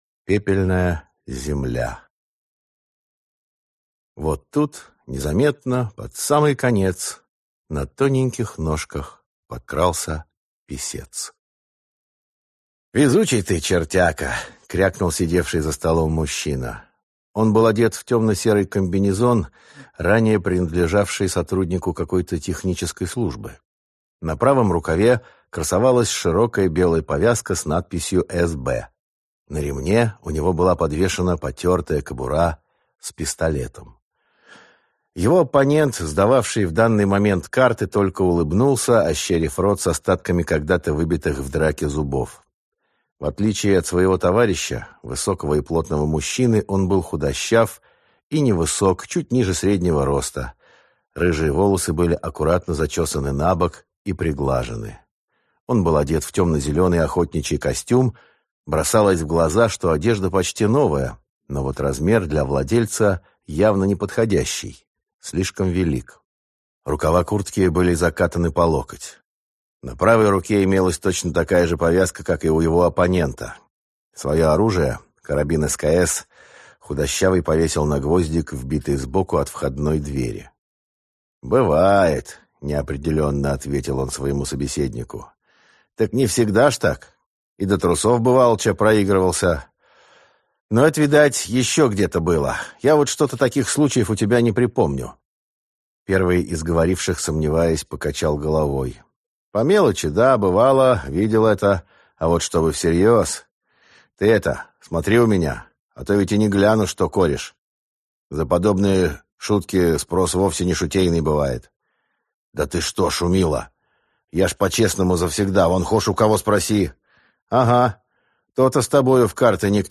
Аудиокнига Пепельная земля | Библиотека аудиокниг